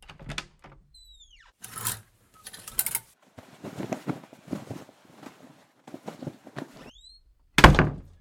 wardrobe_0.ogg